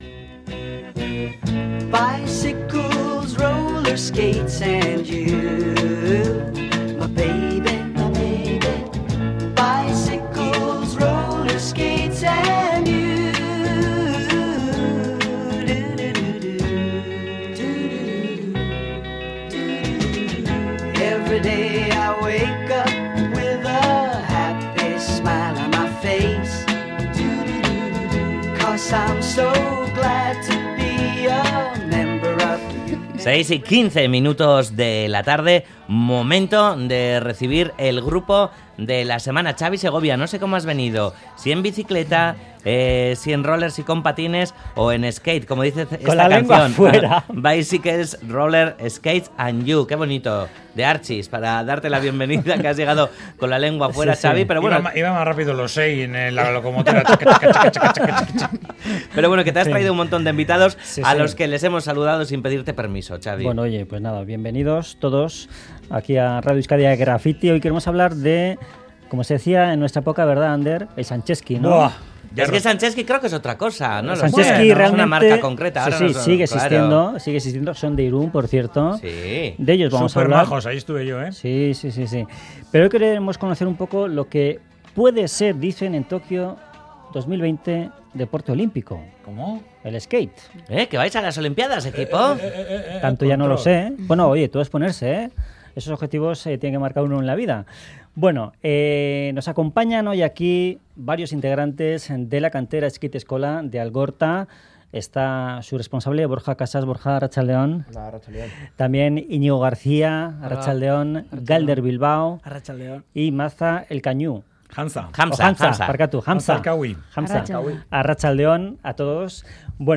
Audio: Alumnos de 'La Kantera' de Getxo nos cuentan los secretos de este deporte que puede ser olímpico en Tokio 2020